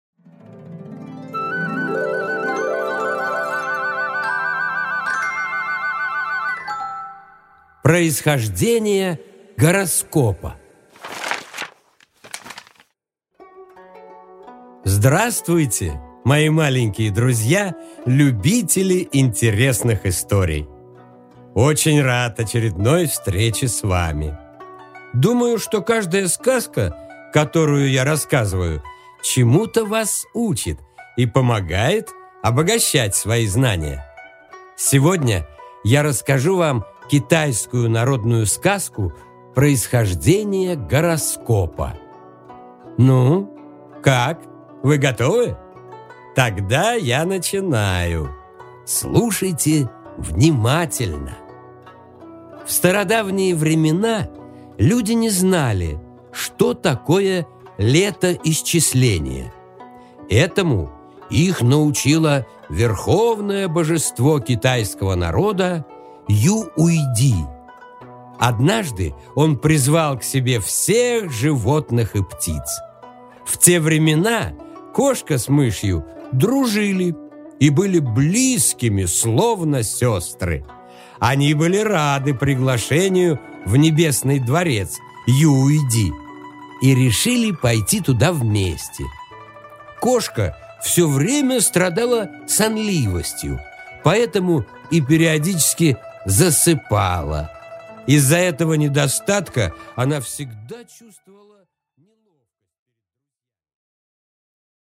Аудиокнига Происхождение гороскопа